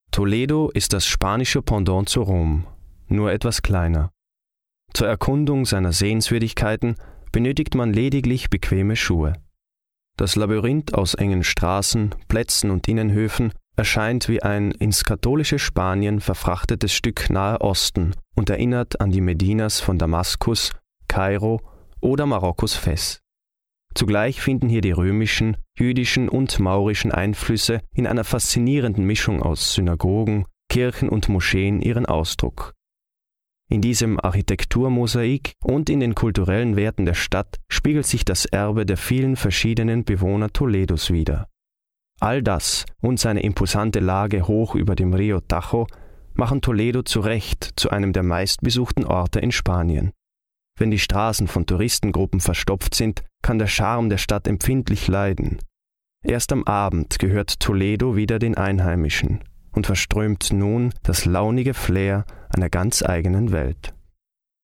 Almanca Seslendirme
Erkek Ses